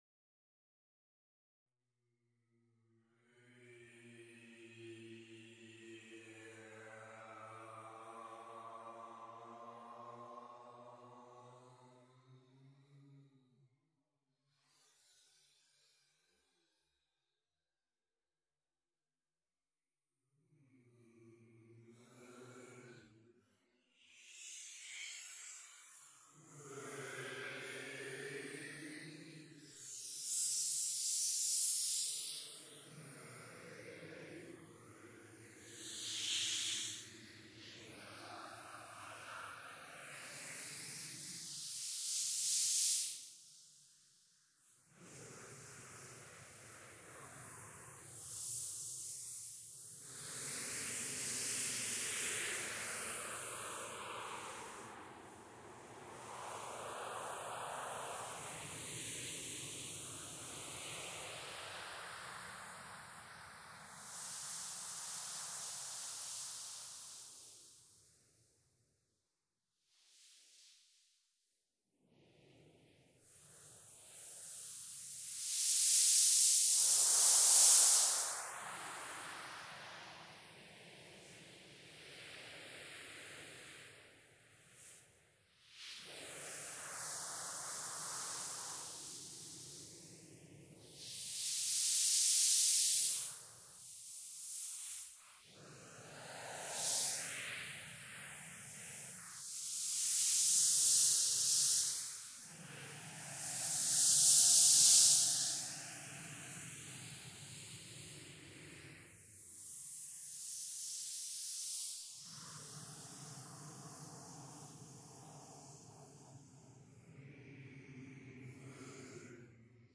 electro-acoustic music